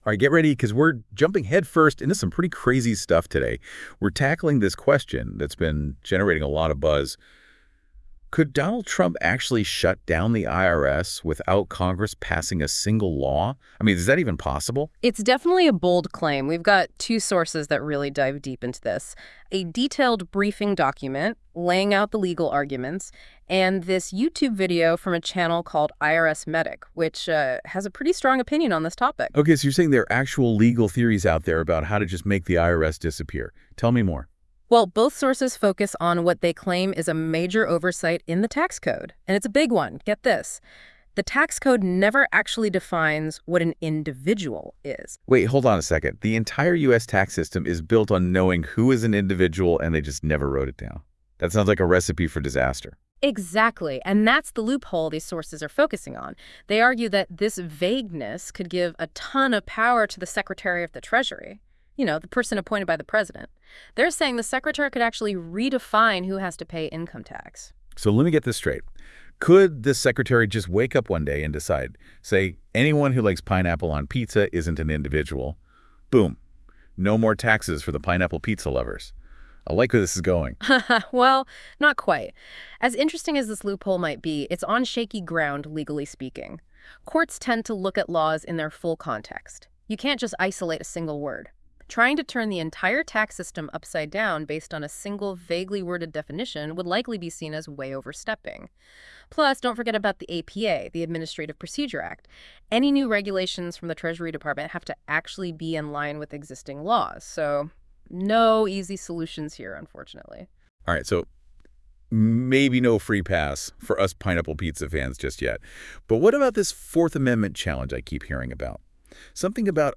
Listen to a convo on the vid…. much more below the conversation